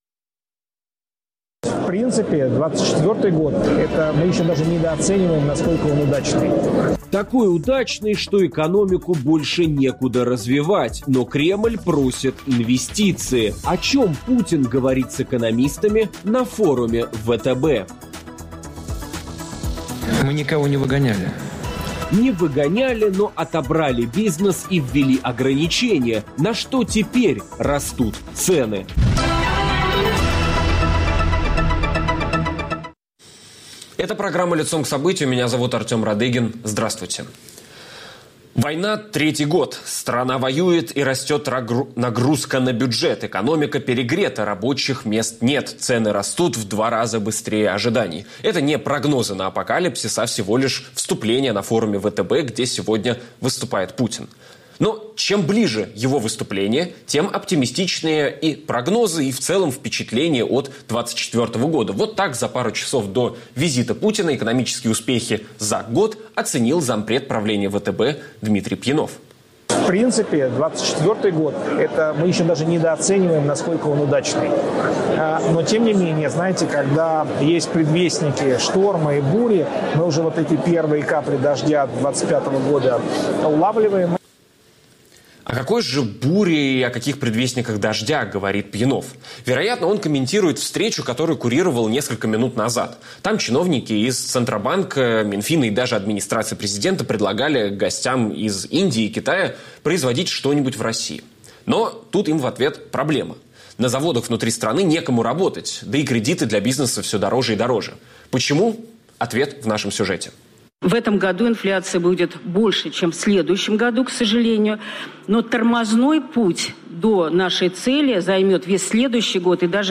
Кто готов вкладываться в военную экономику Путина и как долго россияне готовы терпеть рост цен из-за войны? Обсудим с экономическими обозревателями